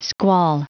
Prononciation du mot squall en anglais (fichier audio)
Prononciation du mot : squall